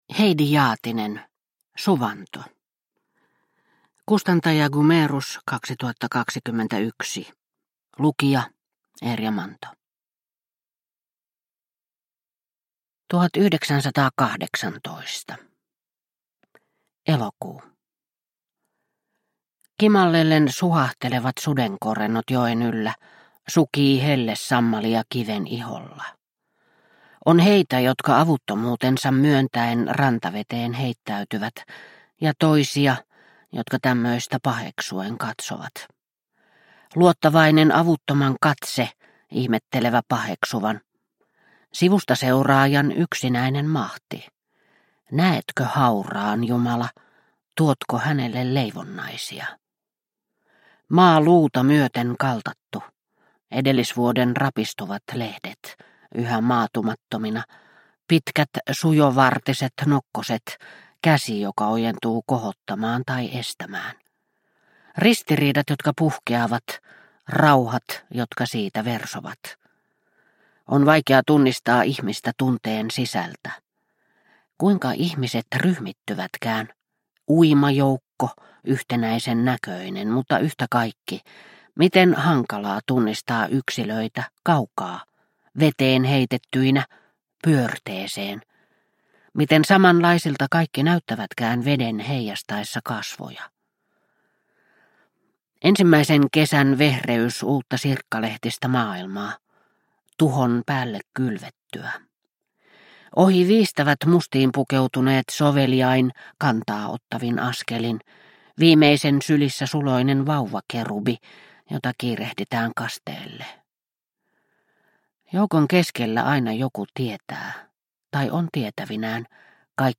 Suvanto – Ljudbok – Laddas ner